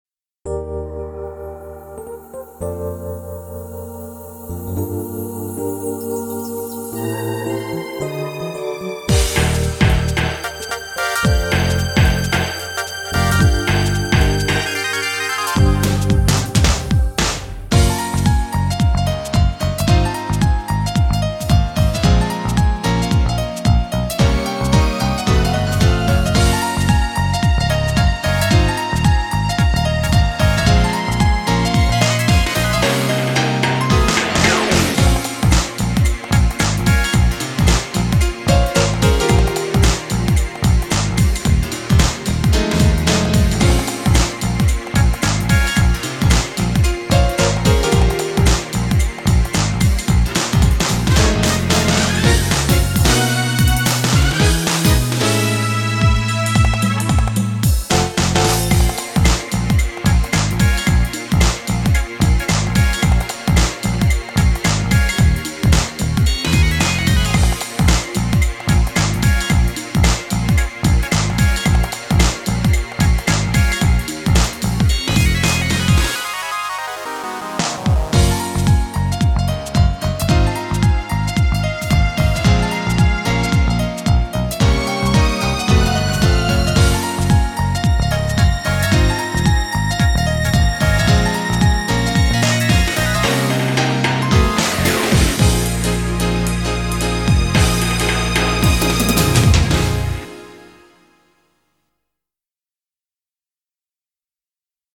BPM111
Audio QualityPerfect (High Quality)
Who's down for some silky jazz?